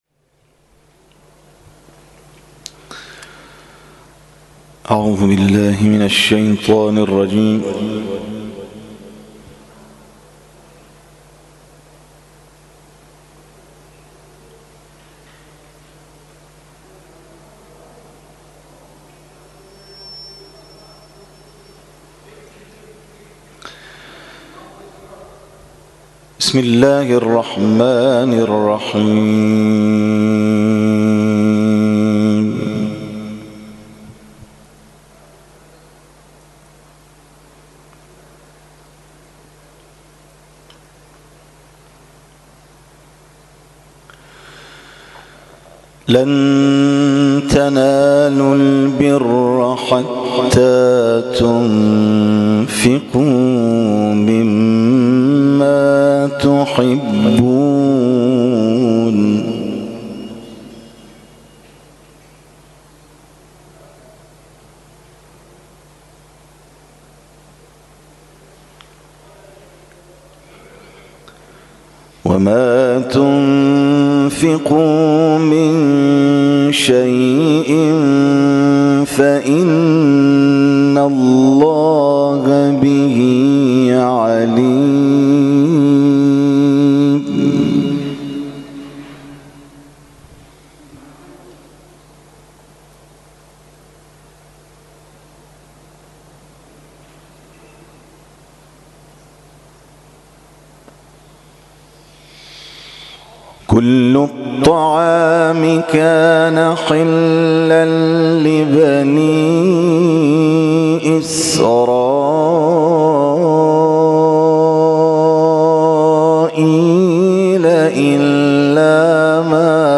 تلاوت
در مسجد جامع المهدی(عج) واقع در فلکه اول دولت‌آباد برگزار شد.